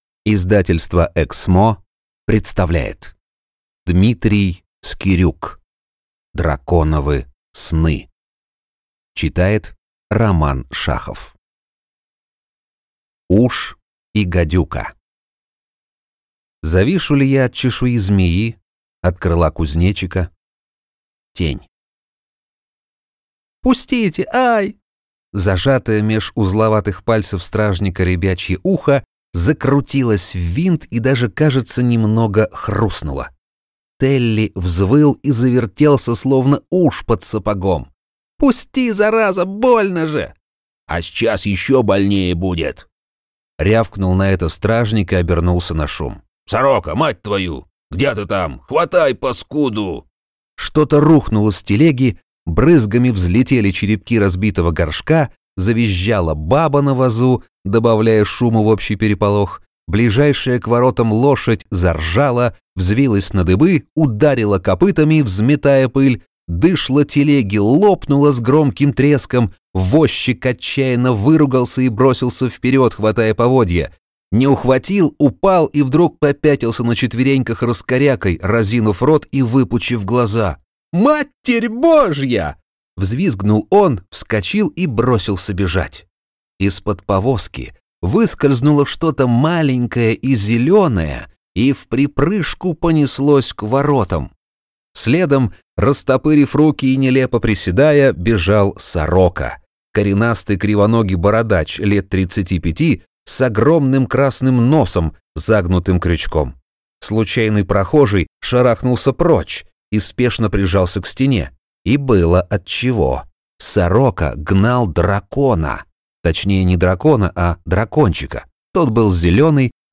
Аудиокнига Драконовы сны | Библиотека аудиокниг